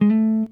SLIDESOLO3.wav